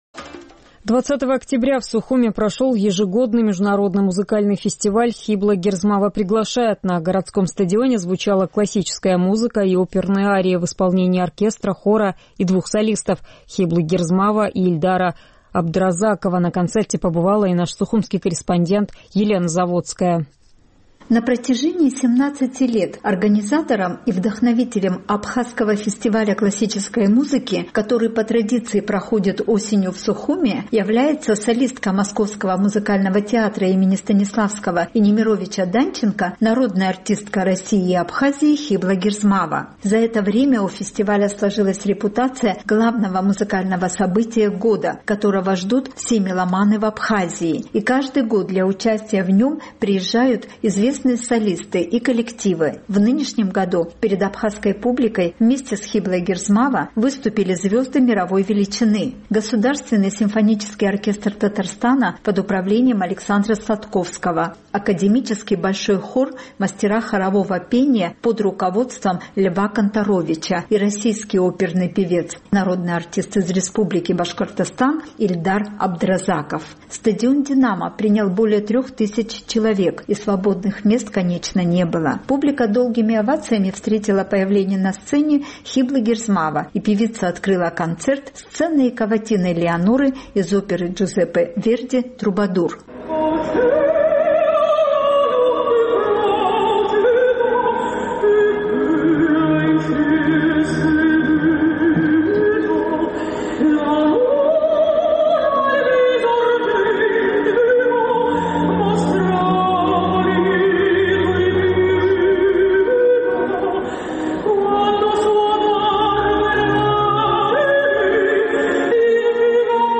20 октября в столице Абхазии прошел ежегодный XVII Международный музыкальный фестиваль «Хибла Герзмава приглашает…» На городском стадионе звучали классическая музыка и оперные арии в исполнении оркестра, хора и двух солистов – Хиблы Герзмава и Ильдара Абдразакова.
Концерт шел два часа и завершился песней «Абхазия моя».